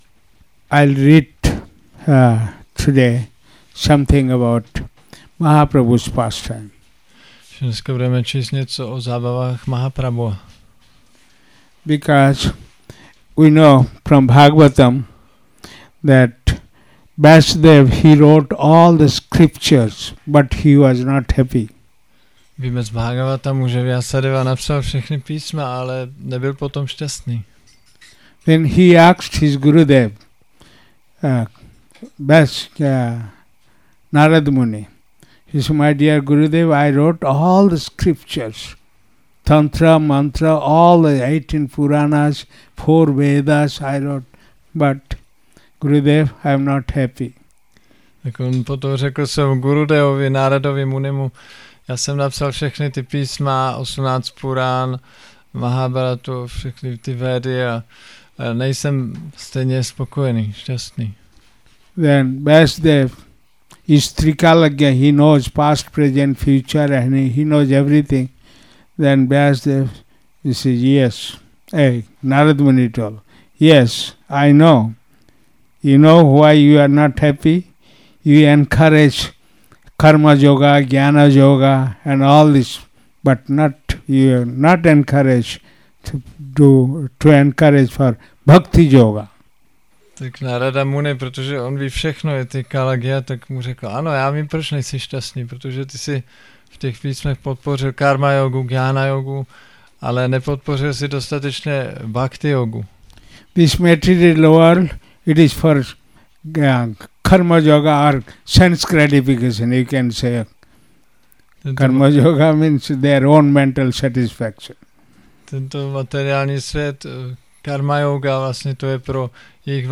Přednáška Caitanya bhagavata – Šrí Šrí Nitái Navadvípačandra mandir